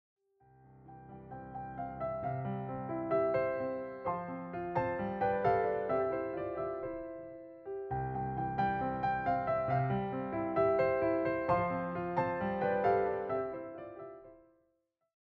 all given a solo piano treatment.